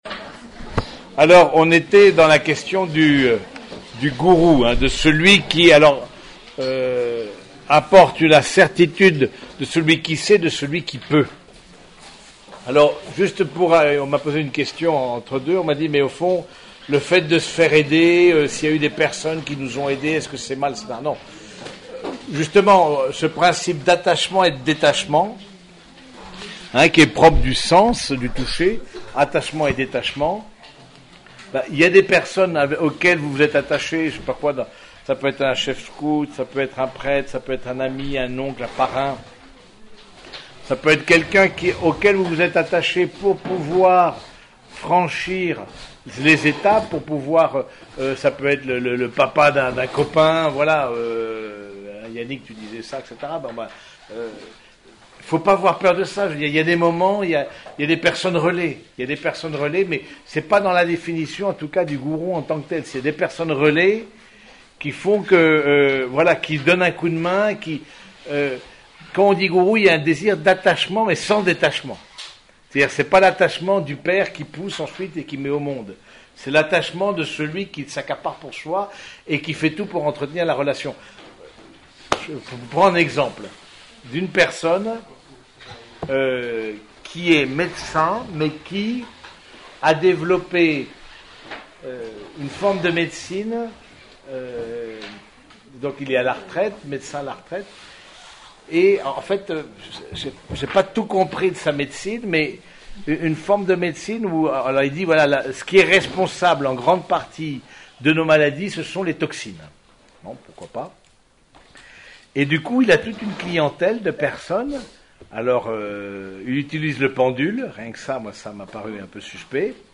Conférences sur l'éducation à travers les 5 sens